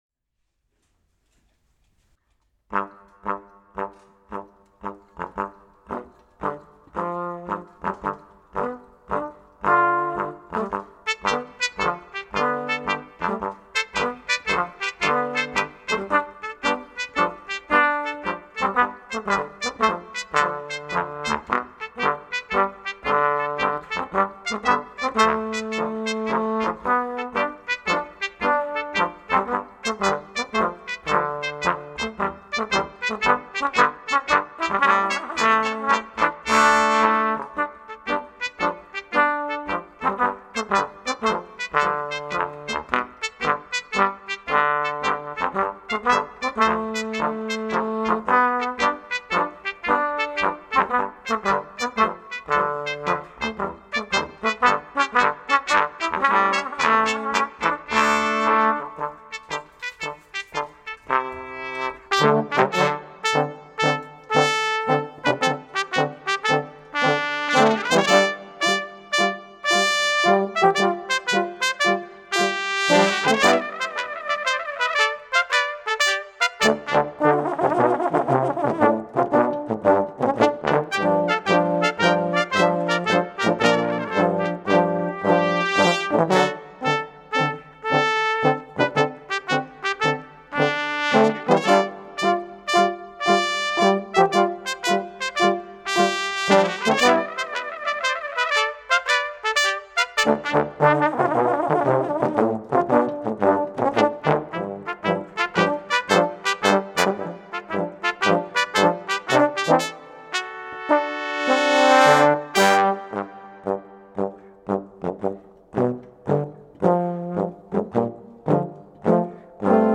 Brass Quintet Edition